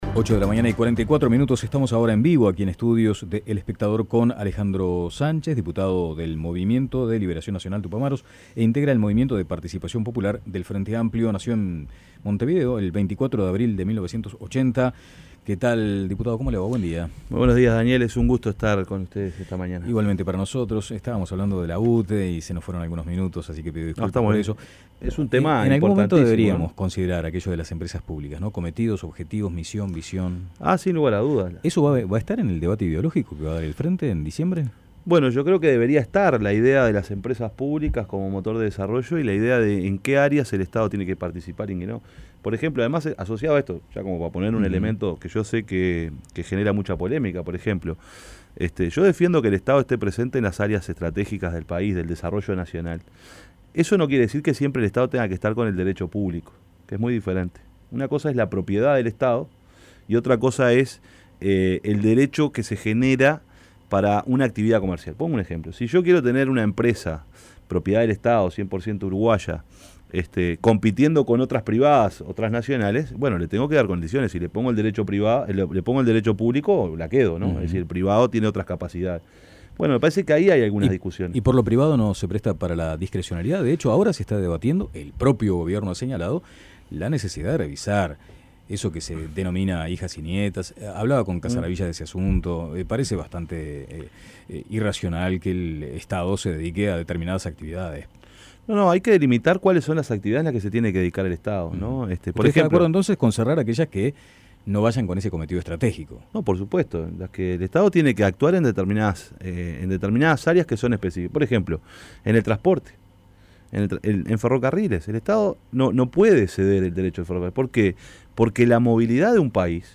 El diputado por el MPP, Alejandro "Pacha" Sánchez, dijo en La Mañana de El Espectador que la posición que tomó el diputado Gonzalo Mujica respecto a apoyar la investigación por los negocios realizados entre el Estado uruguayo y Venezuela "está reñida con la ética".
Entrevista a Alejandro Sánchez